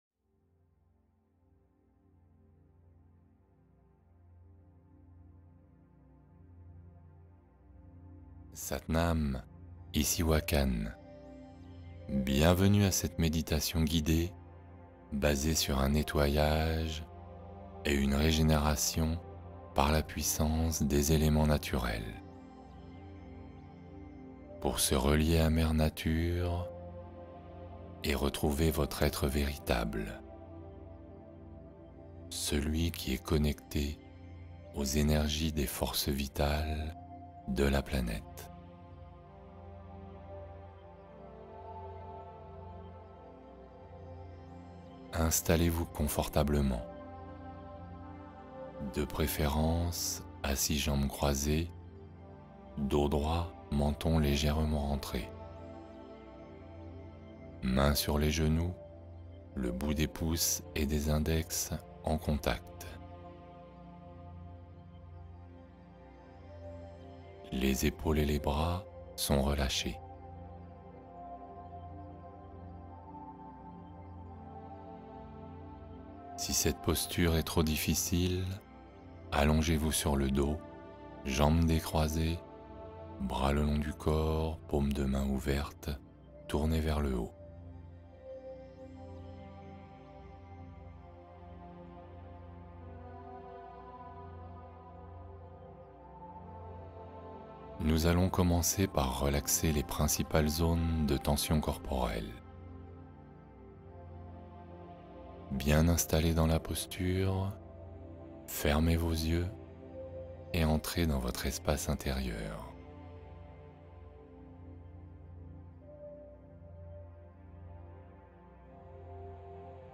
Conte du soir : voyage imaginaire vers un sommeil réparateur